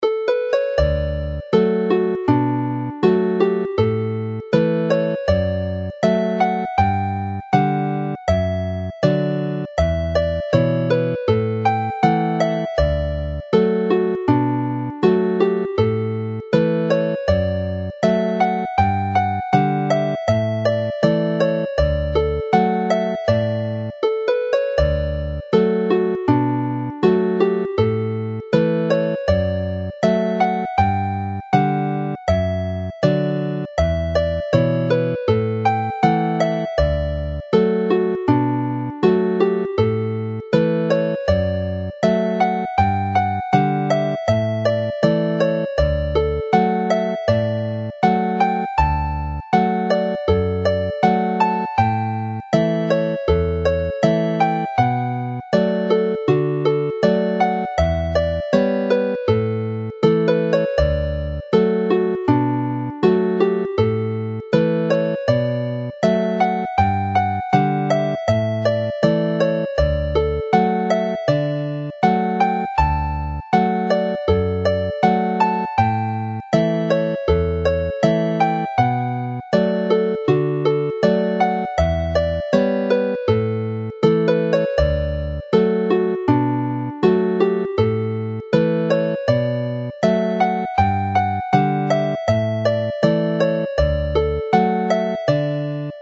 The Mill Street Hornpipe as a reel
Play the tune slowly